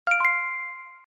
На этой странице собраны звуки, связанные с покупками и оплатой: работа кассового аппарата, сигналы терминалов, уведомления об успешной транзакции.
Звук подтверждения оплаты на Android (платеж Google)